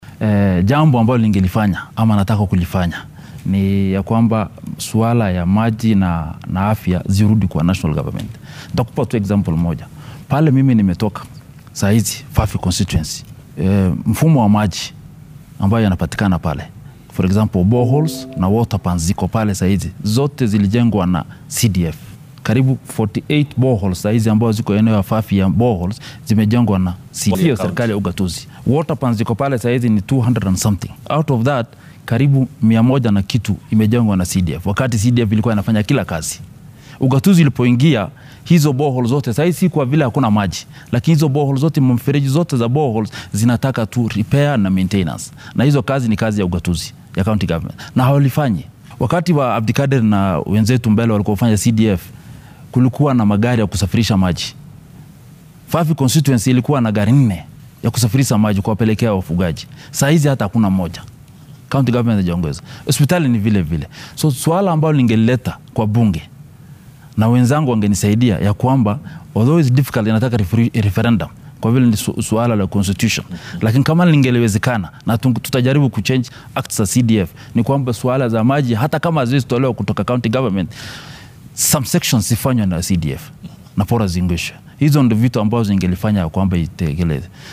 Arrintan ayuu xildhibaanka ka hadlay xilli uu maanta marti ku ahaa barnaamijka telefishinka maxalliga ee Citizen.